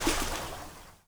SPLASH_Subtle_01_mono.wav